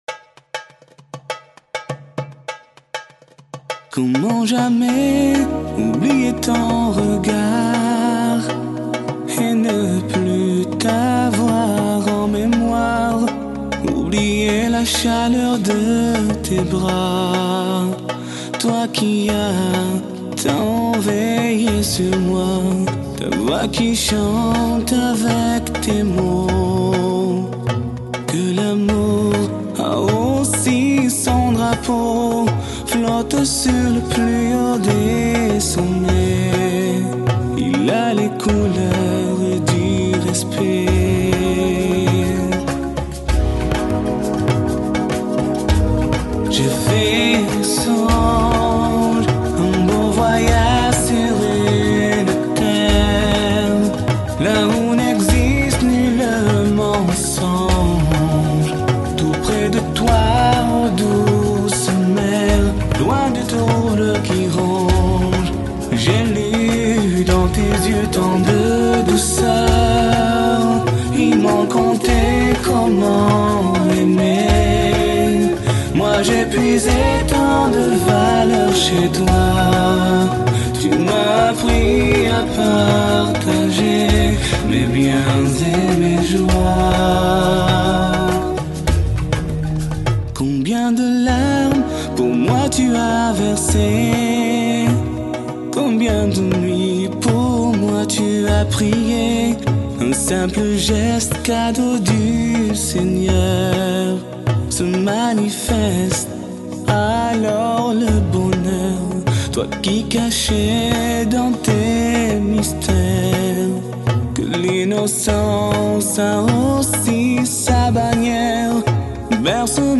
Un chant islamique en l'honneur de nos Mères